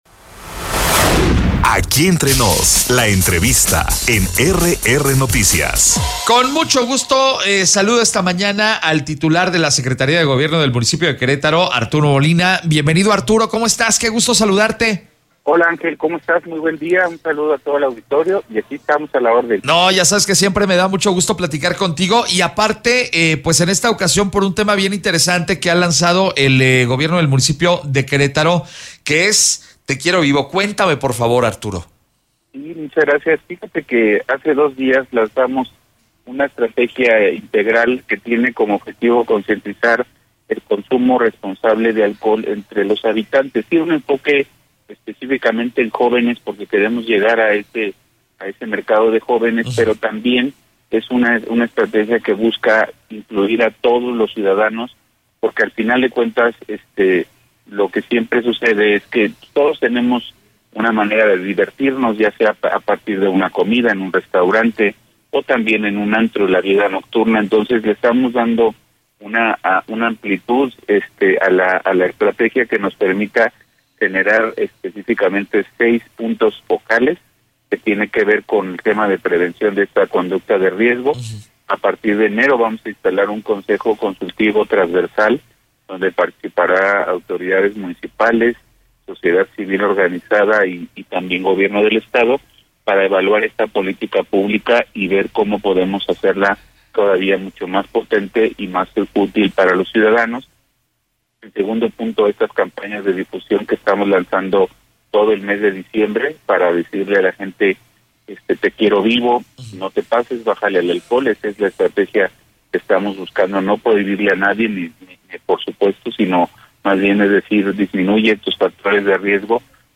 EntrevistasPodcast